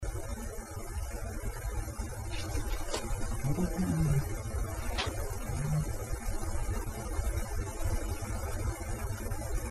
EVP  cz.1
To wyłapałem u siebie w domu z miesiąc temu
wyrażniej to słychać na podbitych decybelach i przepuszczeniu prze filtr w celu poprawienia jakości nagrania ,pierwszy fragment to Oryginał następny to to samo ale przepuszczone przez filtr.
evp1filtr.mp3